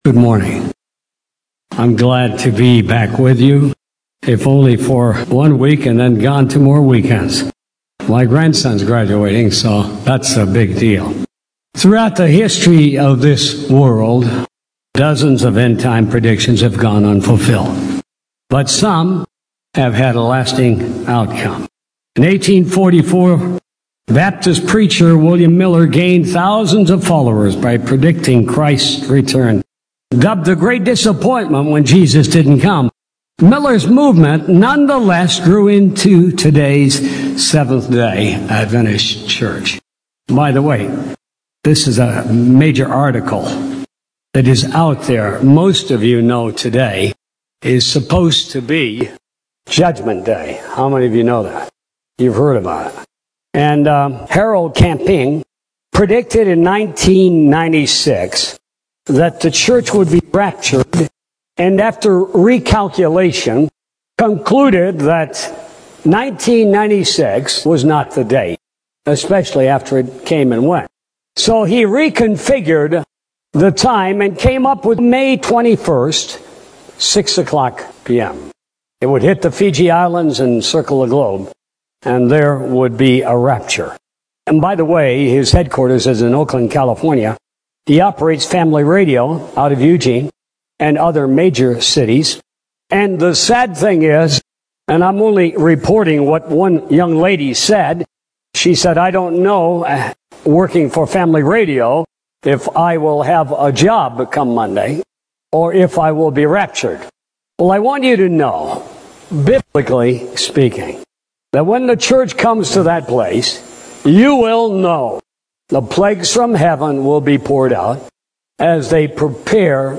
Sermons and Talks from 072709 through 123111